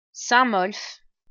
Saint-Molf (French pronunciation: [sɛ̃ mɔlf]